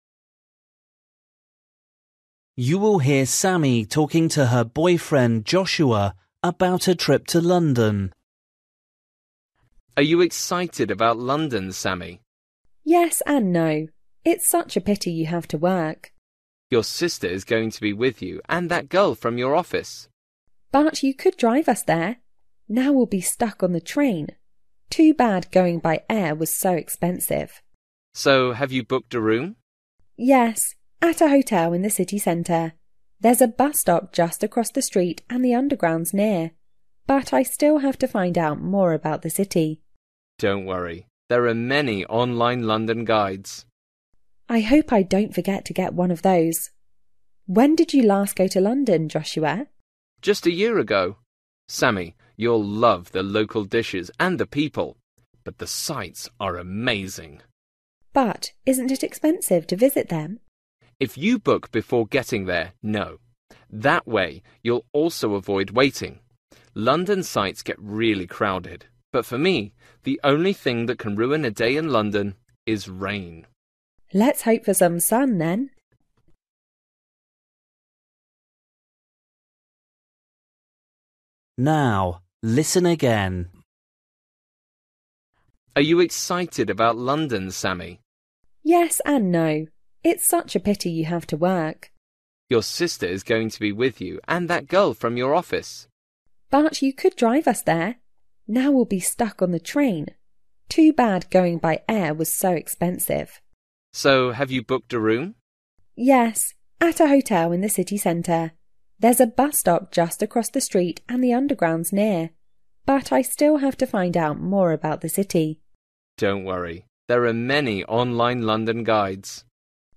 Bài tập trắc nghiệm luyện nghe tiếng Anh trình độ sơ trung cấp – Nghe một cuộc trò chuyện dài phần 30